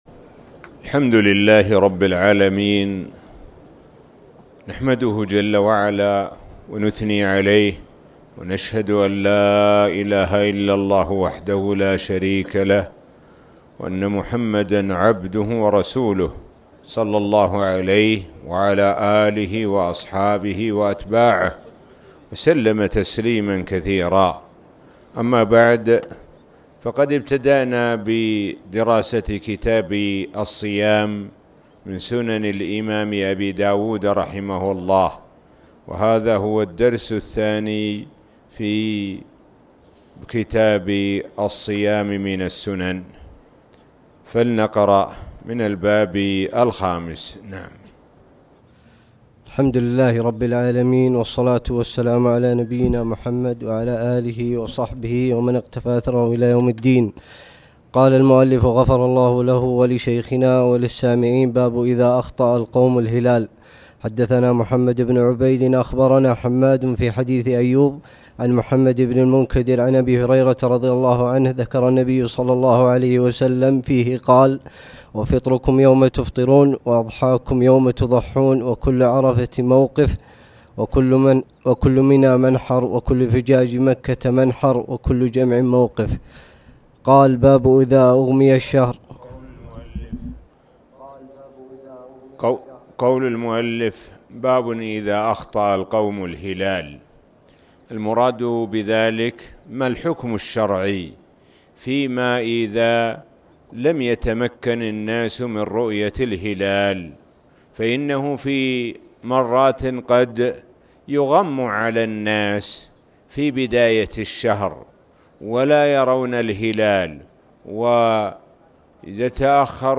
تاريخ النشر ٢٠ رمضان ١٤٣٨ هـ المكان: المسجد الحرام الشيخ: معالي الشيخ د. سعد بن ناصر الشثري معالي الشيخ د. سعد بن ناصر الشثري باب إذا أخطأ القوم الهلال